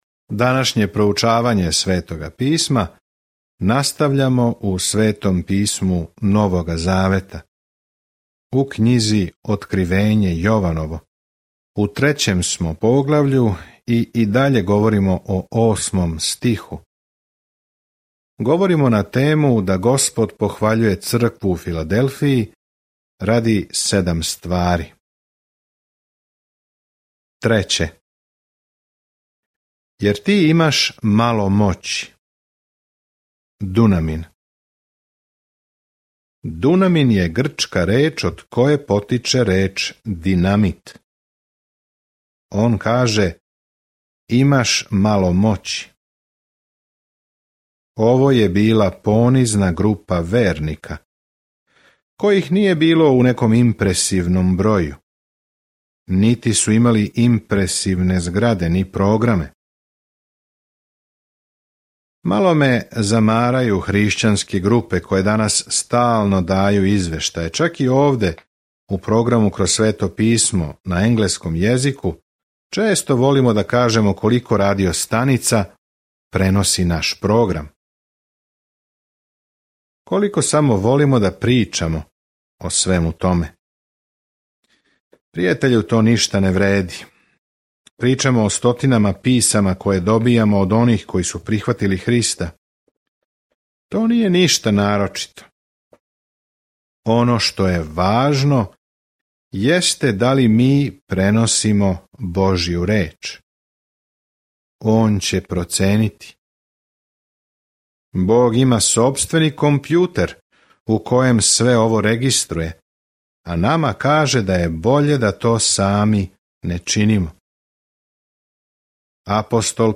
Scripture Revelation 3:9-13 Day 17 Start this Plan Day 19 About this Plan Откривење бележи крај свеобухватне временске линије историје са сликом о томе како ће се коначно обрачунати са злом и како ће Господ Исус Христ владати у свакој власти, моћи, лепоти и слави. Свакодневно путовање кроз Откривење док слушате аудио студију и читате одабране стихове из Божје речи.